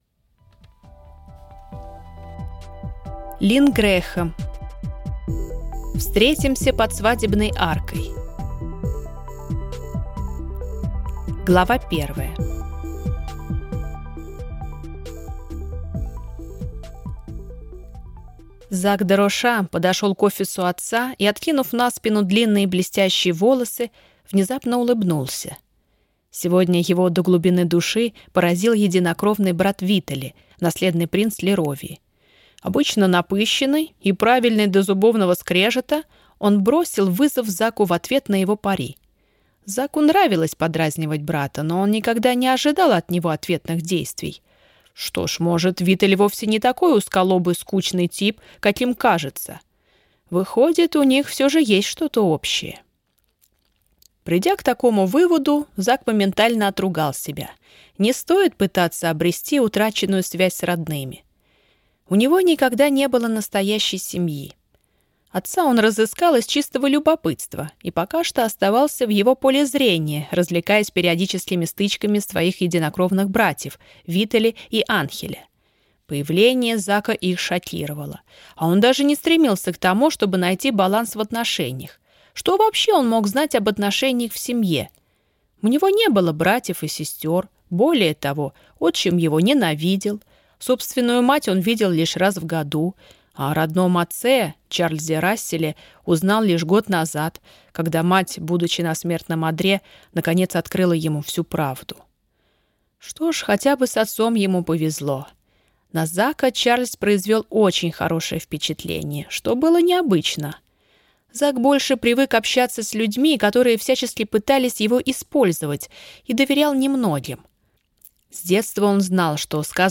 Аудиокнига Встретимся под свадебной аркой | Библиотека аудиокниг